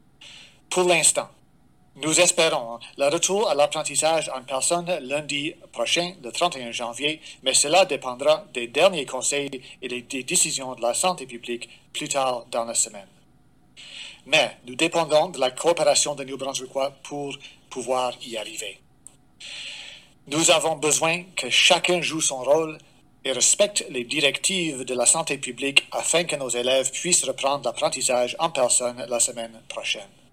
Dominic Cardy, ministre de l'Éducation et du Développement de la petite enfance affirme que la rentrée scolaire aura bel et bien lieu le 31 janvier pour tous les élèves à travers la province :